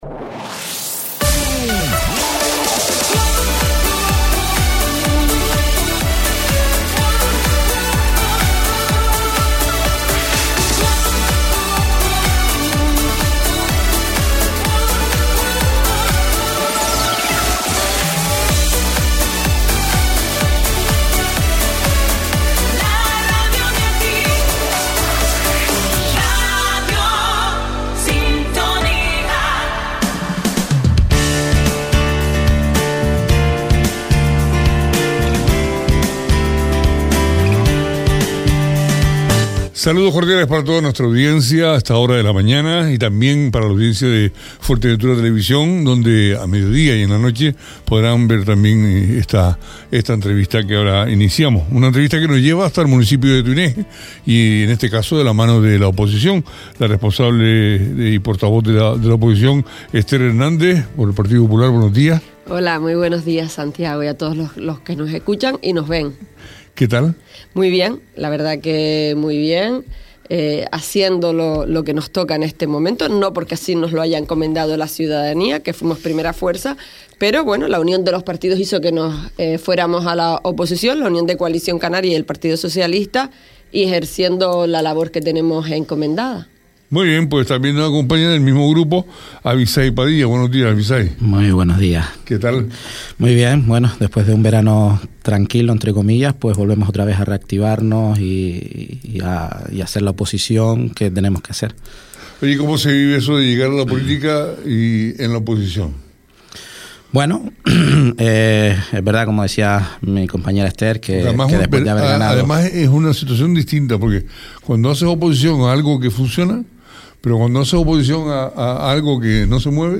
Esta mañana, nos han acompañado en los estudios de Radio Sintonía los concejales de PP en el Ayuntamiento de Tuineje, Esther Hernández y Abisay Padilla
Entrevistas